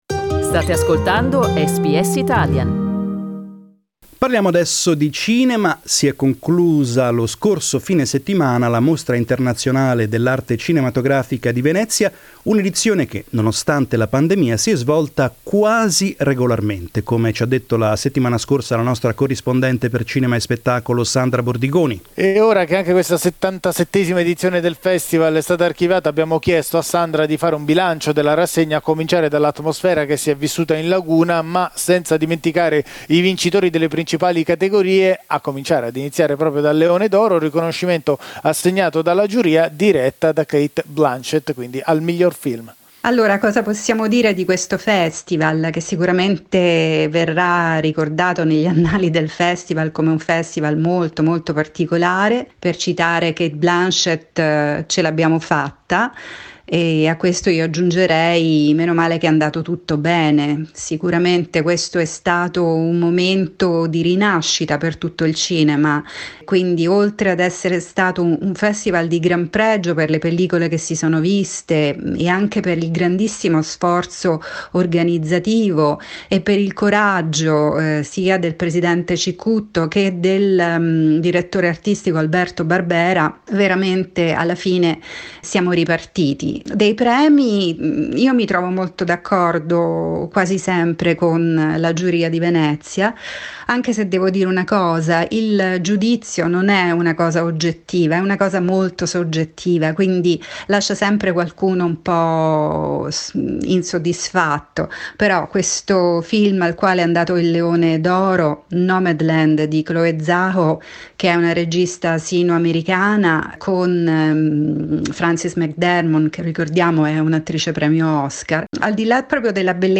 Showbiz correspondent